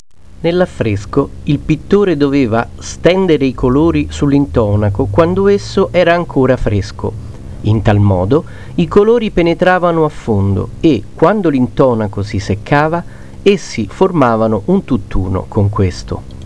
A series of readings on audiocassette (some on CDROM), aimed at improving listening comprehension and introducing specific vocabulary to students of all levels.
All recordings are by native Italian speakers.